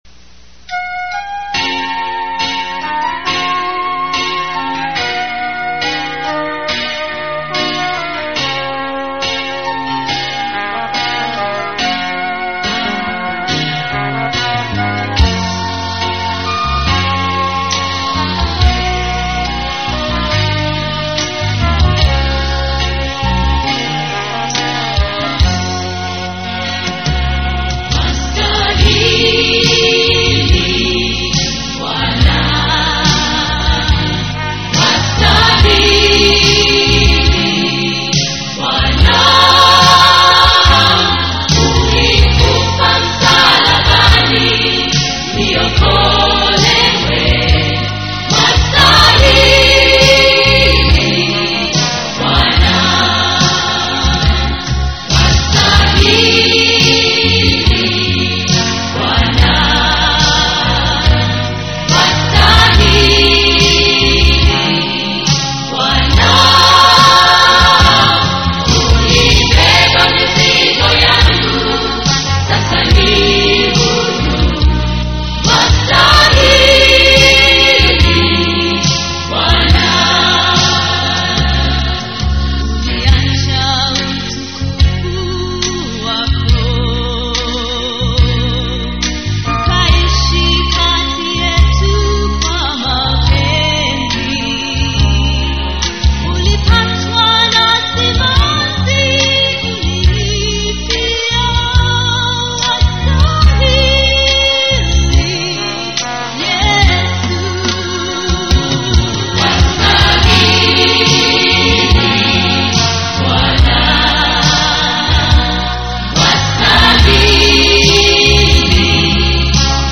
Swahili Songs
This music is all Christian music.